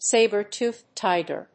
アクセントsáber‐tòothed tíger